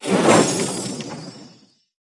Media:RA_Wizard_Evo.wav UI音效 RA 在角色详情页面点击初级、经典和高手形态选项卡触发的音效